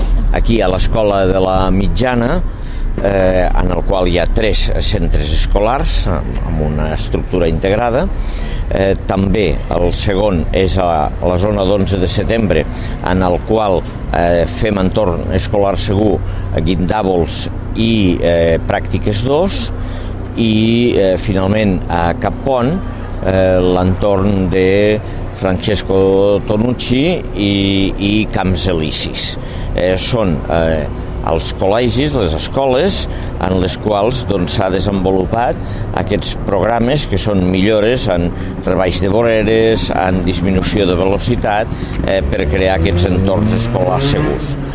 tall-de-veu-de-lalcalde-de-lleida-angel-ros-sobre-millores-en-seguretat-viaria-a-la-ciutat